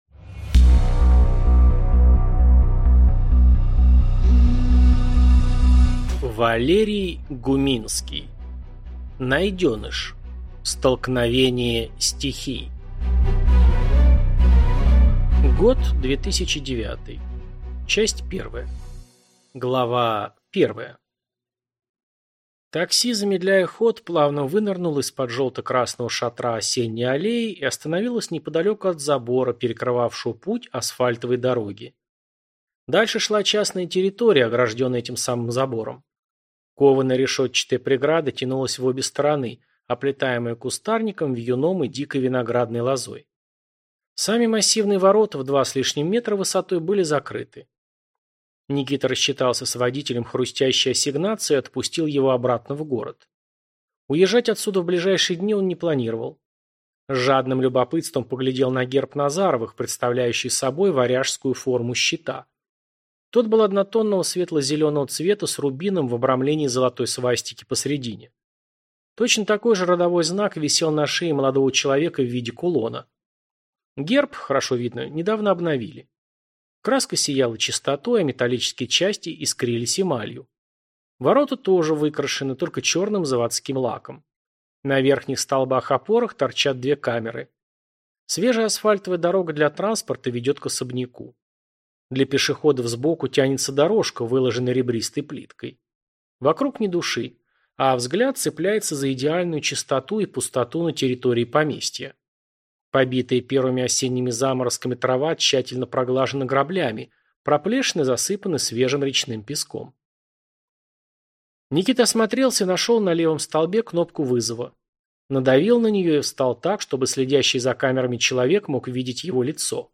Аудиокнига Найденыш. Столкновение стихий | Библиотека аудиокниг